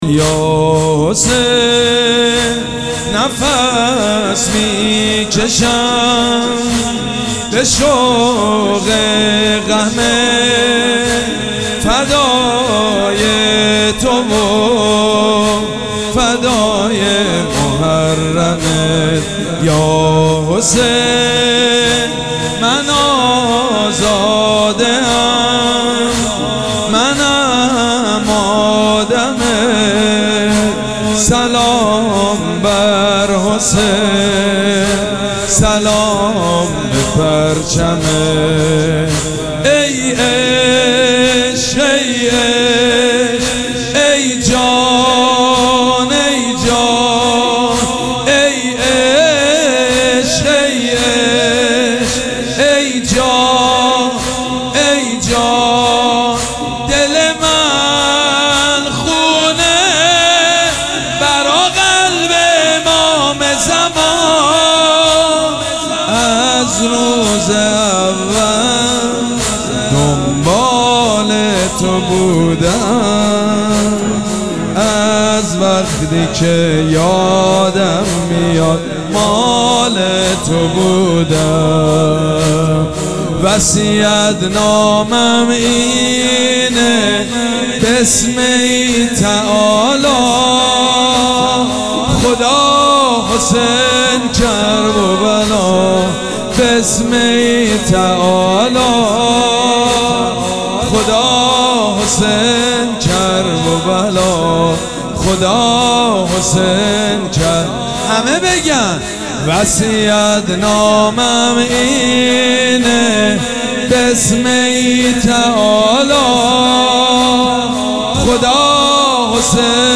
شب پنجم مراسم عزاداری اربعین حسینی ۱۴۴۷
مداح
حاج سید مجید بنی فاطمه